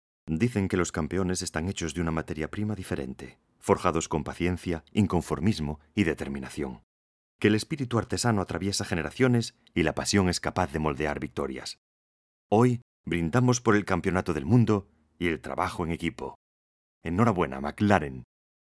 Locucion-McLaren-ESP-001_Correcto.mp3